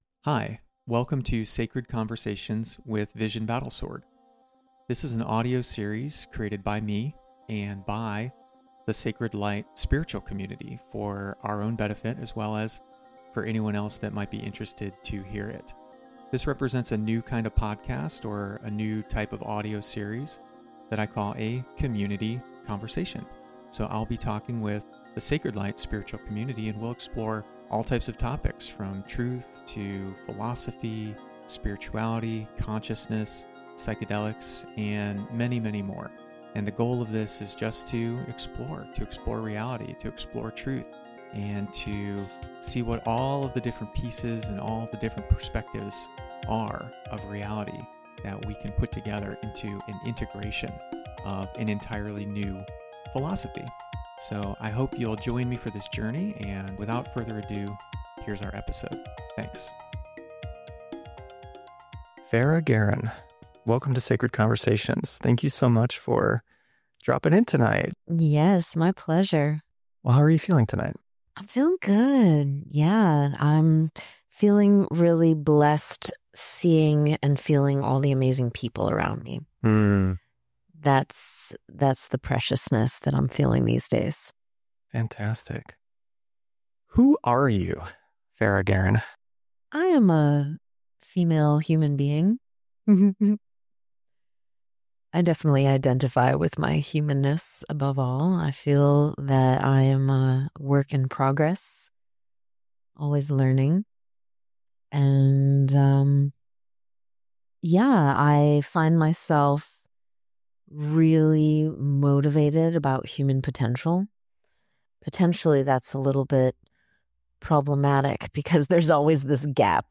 conversation31-nature.mp3